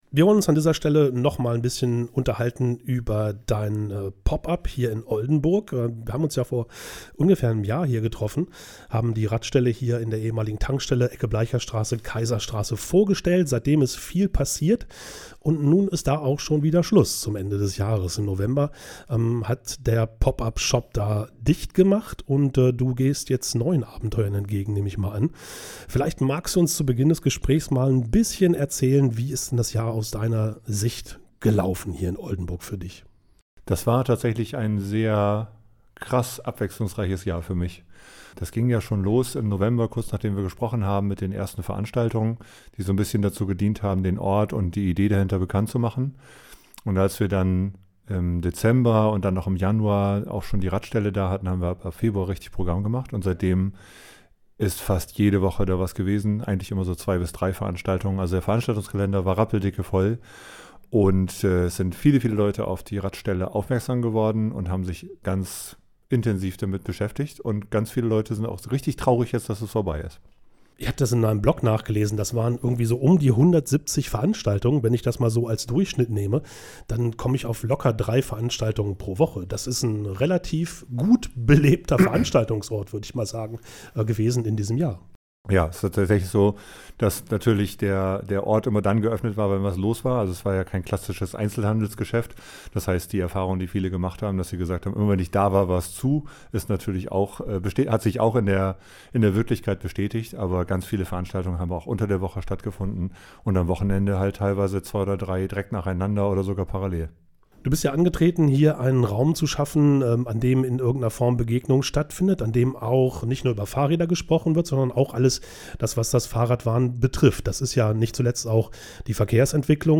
Interview OLDENBURG EINS Herunterladen.